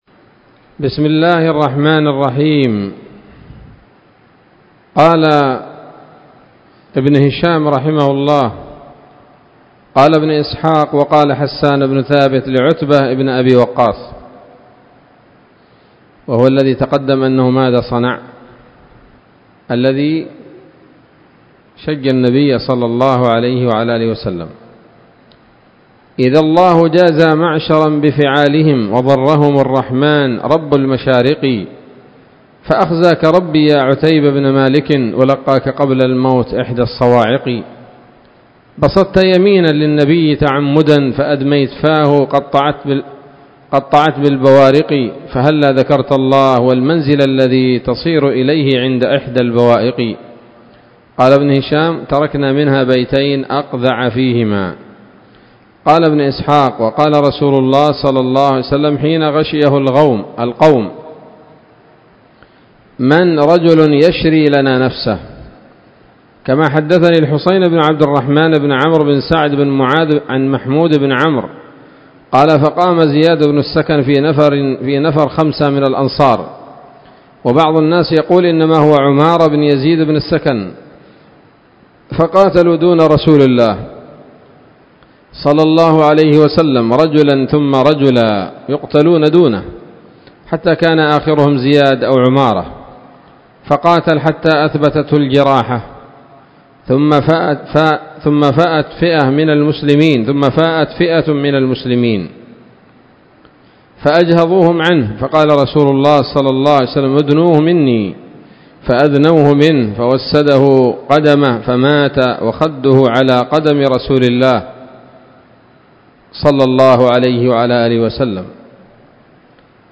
الدرس التاسع والخمسون بعد المائة من التعليق على كتاب السيرة النبوية لابن هشام